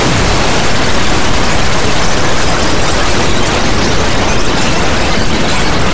ALIENNOISE-R.wav